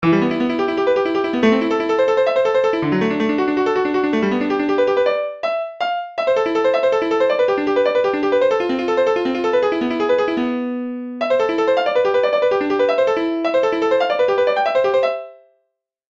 я сначала подумал что это свип:gg: